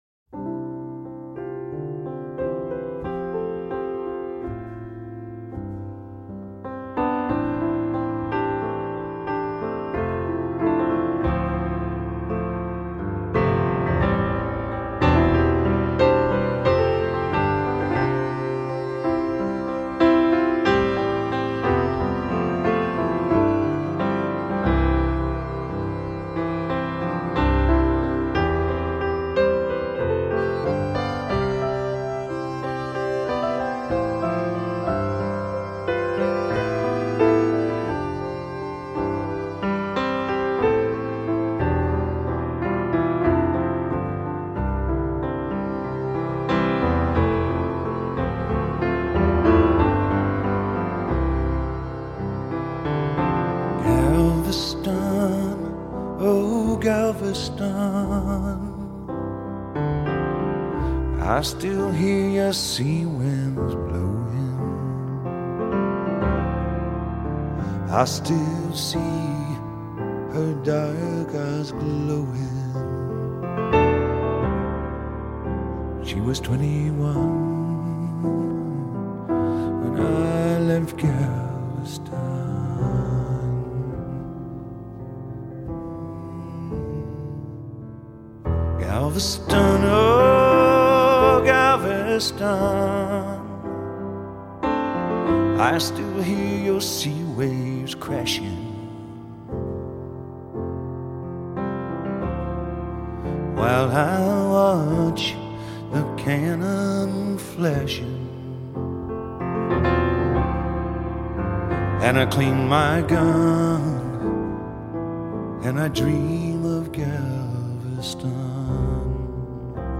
it is the antithesis of Country.